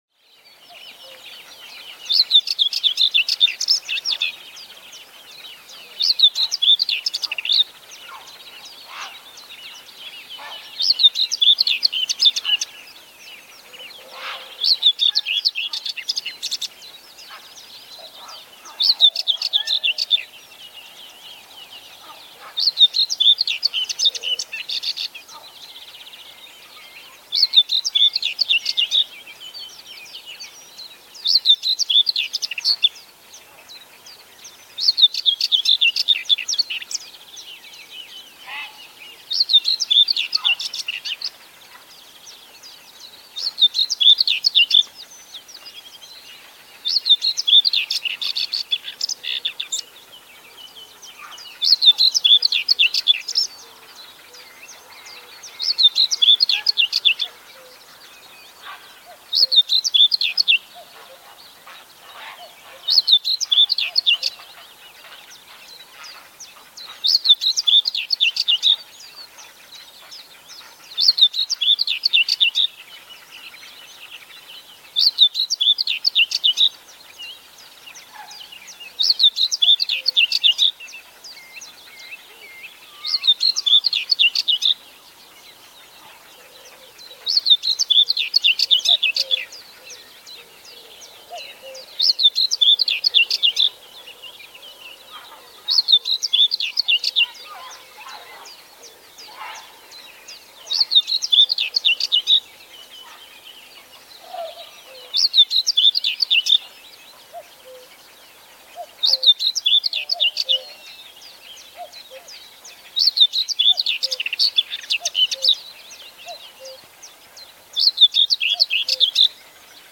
Common whitethroat Tornsanger Серая славка sound effects free download